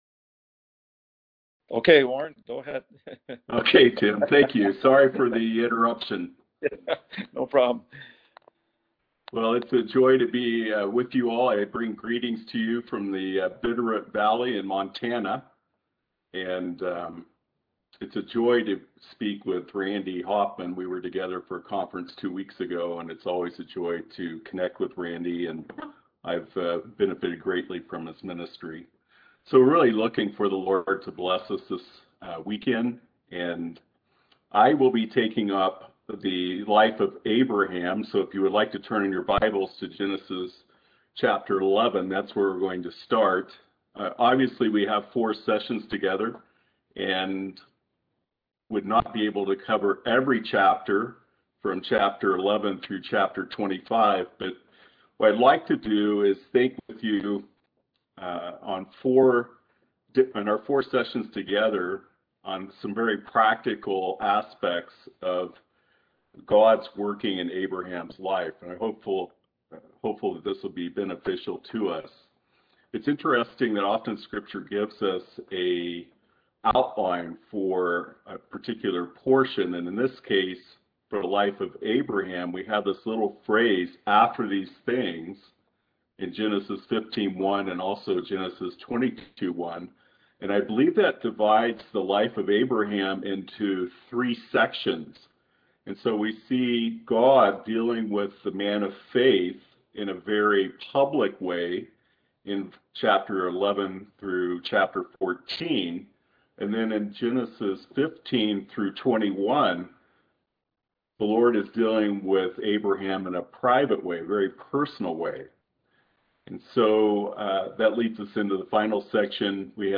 Passage: Genesis 11-13 Service Type: Seminar